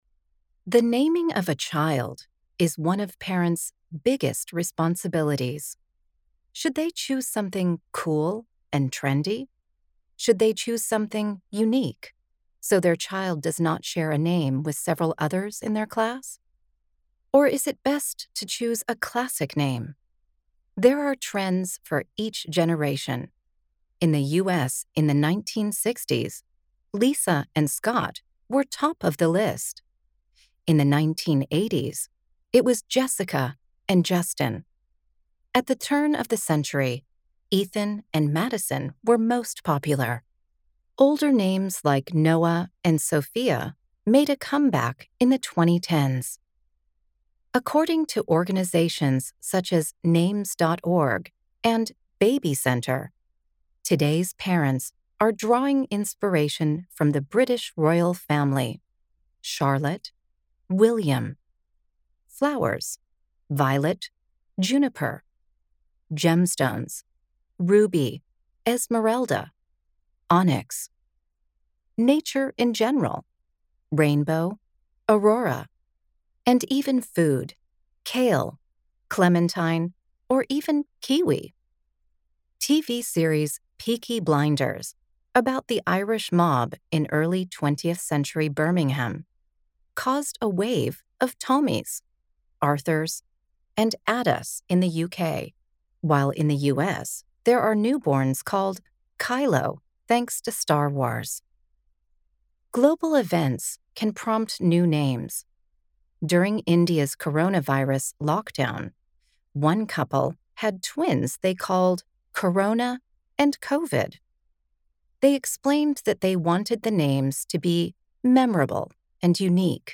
Speaker (American accent)